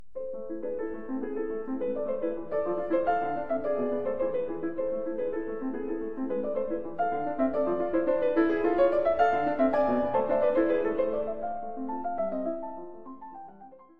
Uitgevoerd door Mitsuko Uchida.
Mozart-Klaviersonate-Amin_3Satz_Uchida_Anf.mp3